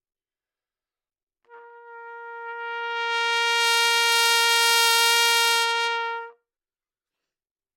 小号单音（吹得不好） " 小号 Asharp4 坏动态错误
描述：在巴塞罗那Universitat Pompeu Fabra音乐技术集团的goodsounds.org项目的背景下录制。单音乐器声音的Goodsound数据集。 instrument :: trumpetnote :: Asharpoctave :: 4midi note :: 58microphone :: neumann U87tuning reference :: 442goodsoundsid :: 2926 故意扮演坏动态错误的一个例子